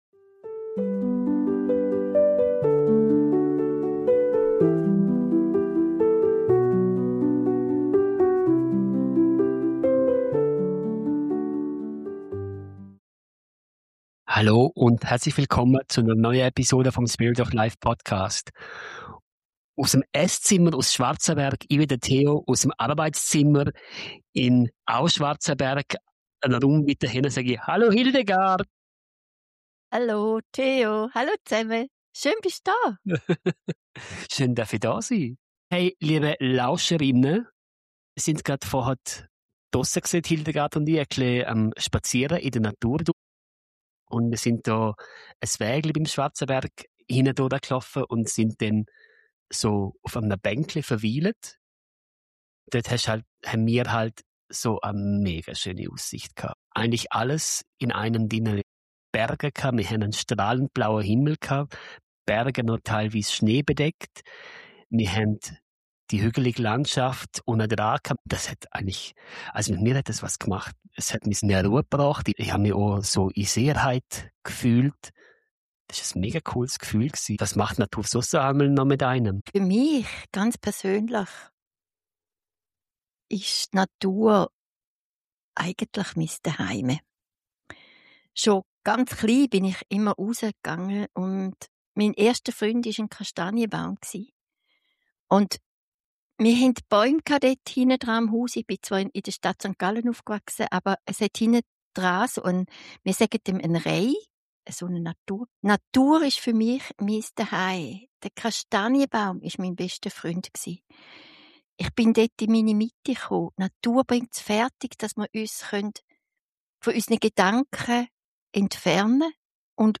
Ein besonderer Teil dieser Episode ist eine geführte kurze Naturreise, die zeigt, wie einfach es sein kann, auch ohne physisch draussen zu sein, wieder in Verbindung zu kommen.